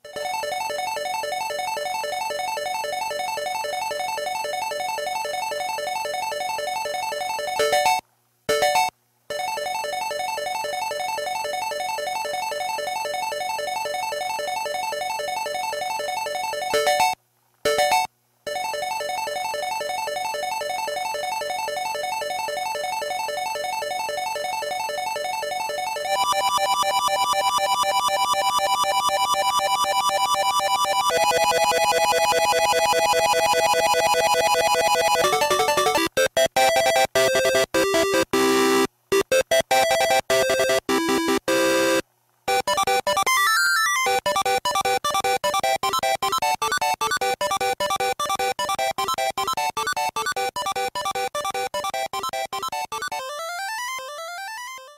<ファンファーレ>
ファンファーレ.mp3